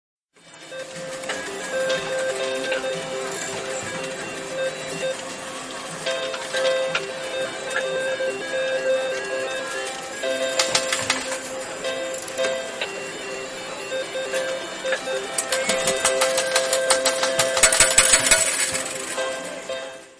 17. Игровой зал казино с однорукими бандитами
zvuk-igrovogo-zala-cazino.mp3